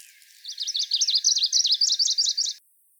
tp 10 : evolution genomes     ECE chant des oiseaux
chant Obscuratus